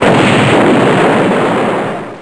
Explosion.snd